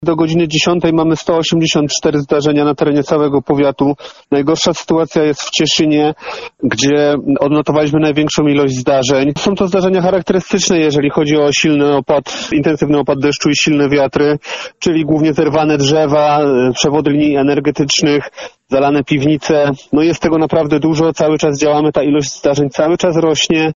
Radio BIELSKO - Nawałnica przeszła nad miastem - Wiadomości